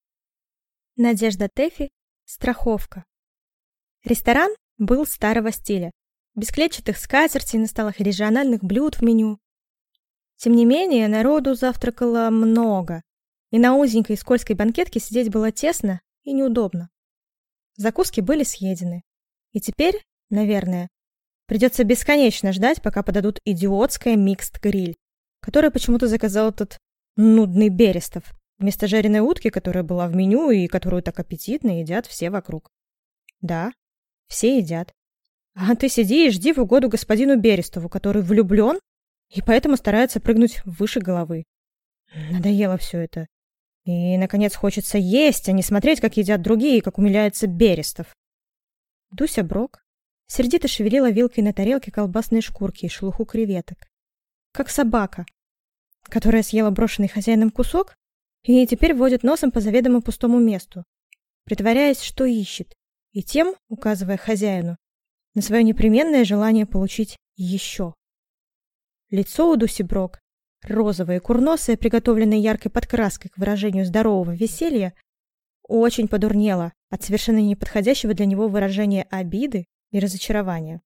Аудиокнига Страховка | Библиотека аудиокниг